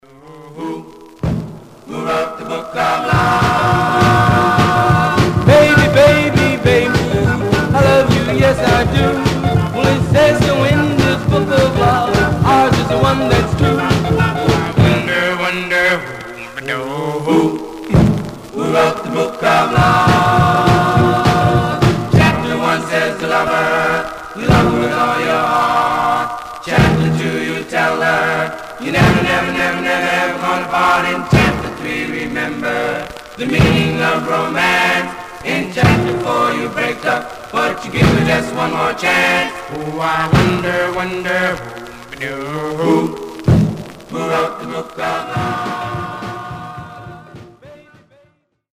Much surface noise/wear
Male Black Group